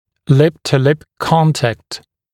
[lɪp-tə-lɪp ‘kɔntækt][лип-ту-лип ‘контэкт]межгубый контакт, полное смыкание губ